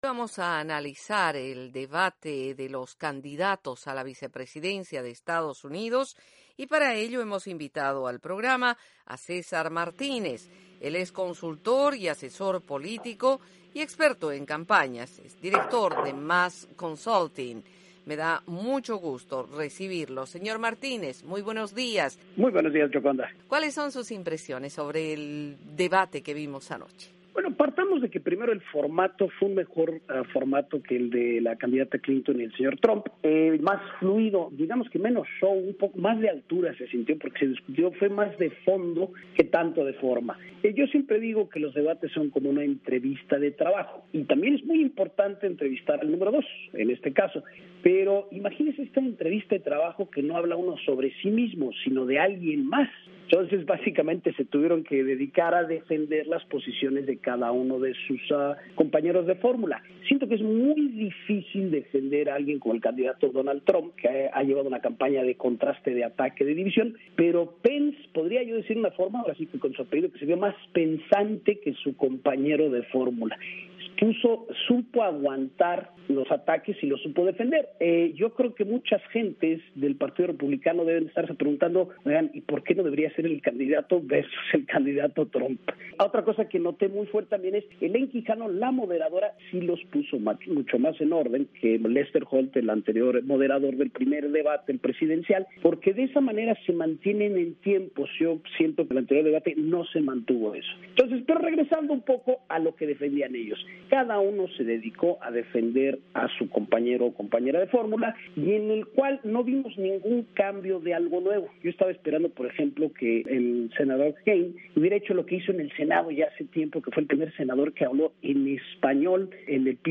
En entrevista con la Voz de América